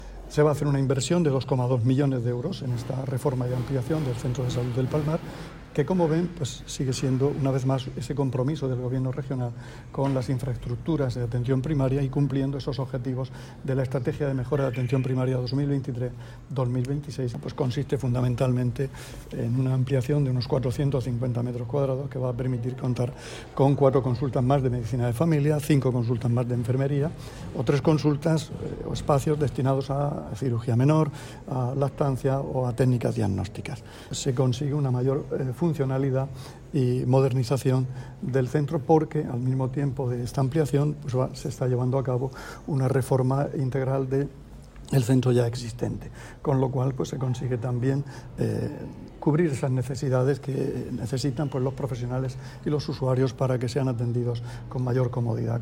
Declaraciones del consejero de Salud, Juan José Pedreño, sobre las obras de ampliación y reforma del Centro de Salud de El Palmar.